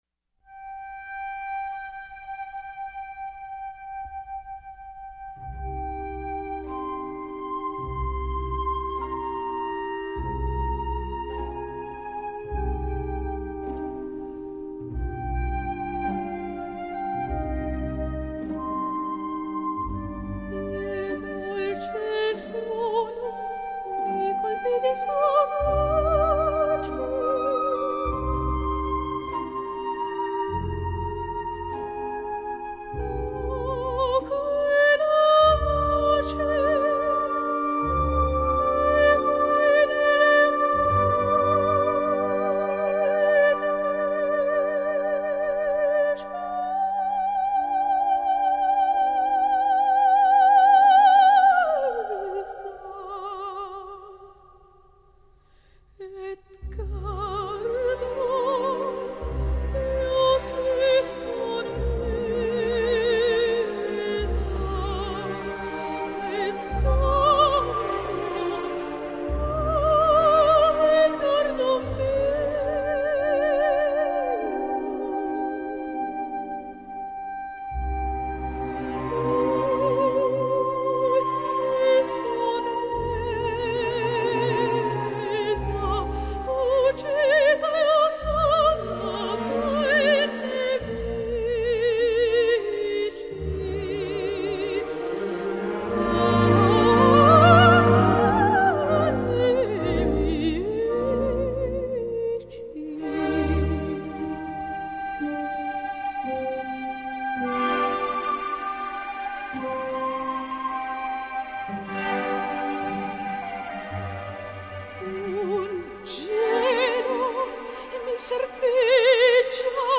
节奏明快的